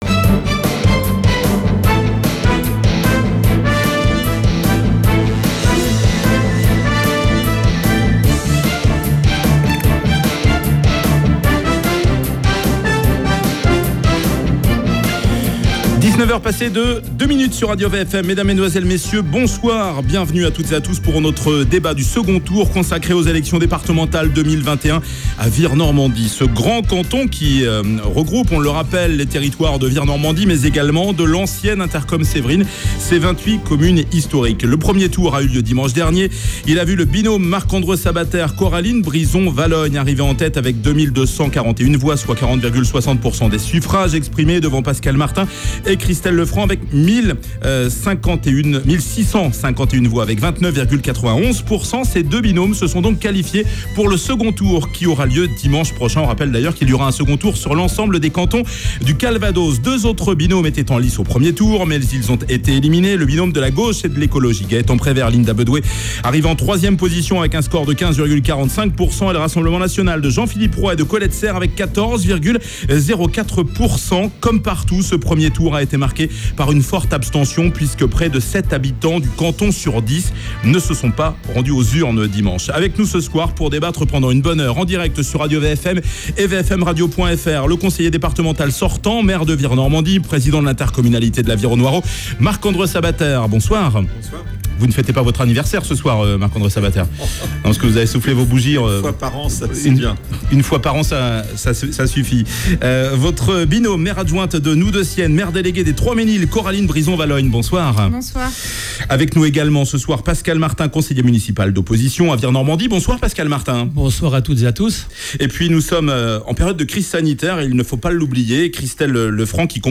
Débat Vire Normandie/Noue de Sienne